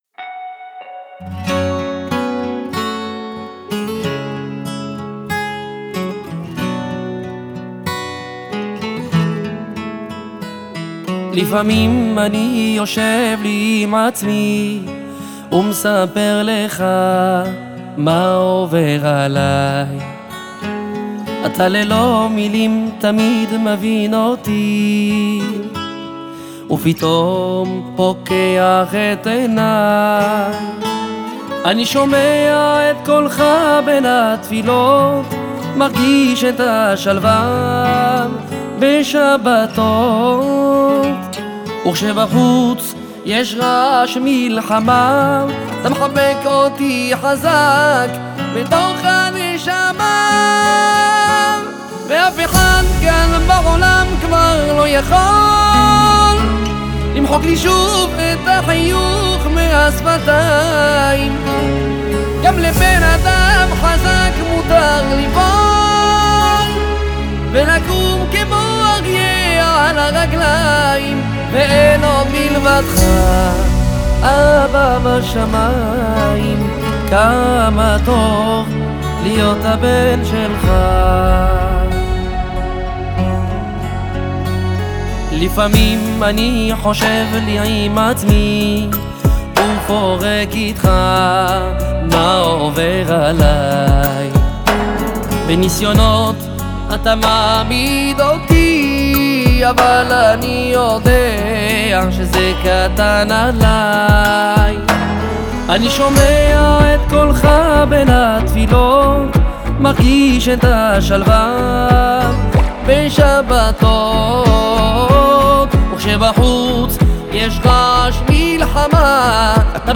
מביא עמו סגנון ייחודי המשלב מזרח ומערב, מסורת וחדשנות